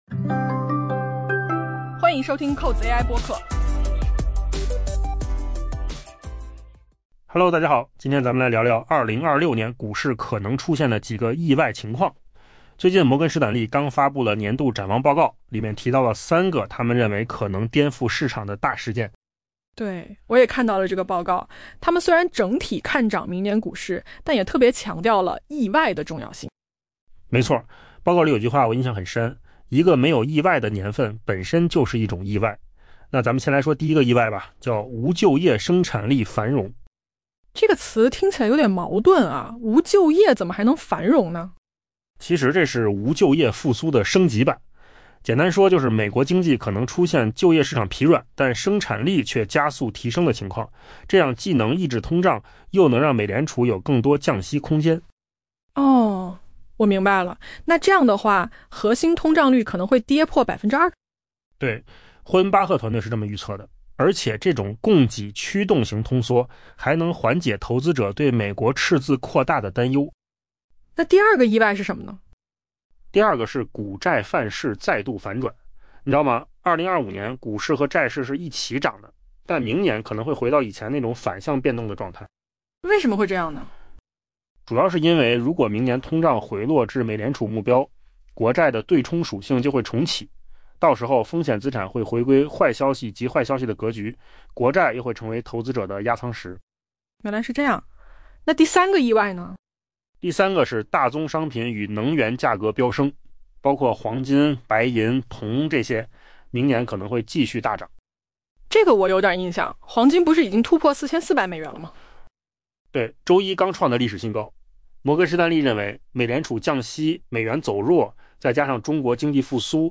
AI 播客：换个方式听新闻 下载 mp3 音频由扣子空间生成 预测人士普遍预计，明年股市将延续向好态势，但总有可能出现意外和风险，颠覆当前展望。